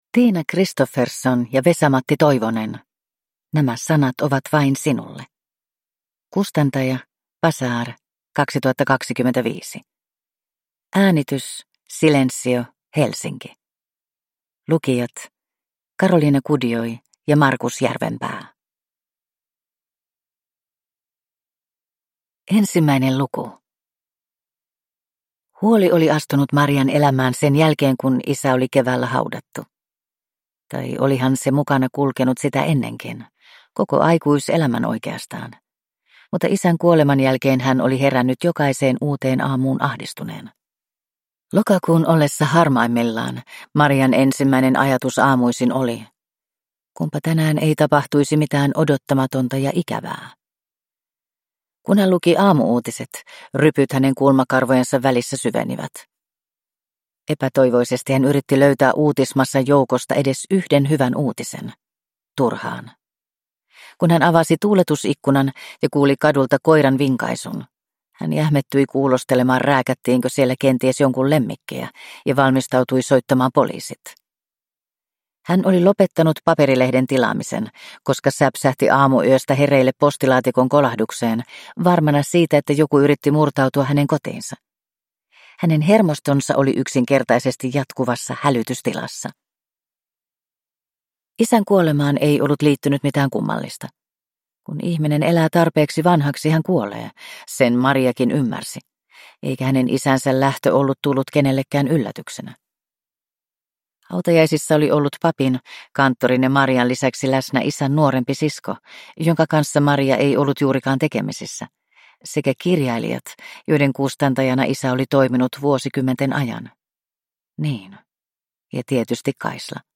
Nämä sanat ovat vain sinulle – Ljudbok